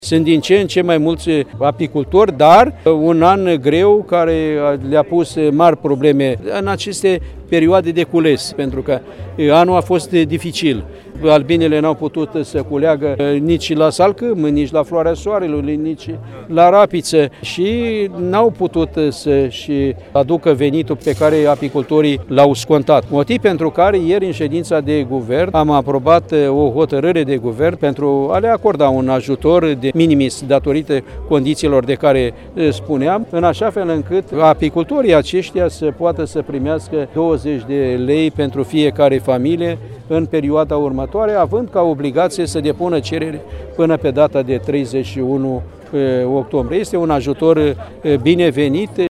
Apicultorii s-au plâns ministrului că în această perioadă au avut de suferit din cauza capriciilor vremii. Tocmai de aceea, a menționat Petre Daea, ministerul vine în sprijinul lor cu un program: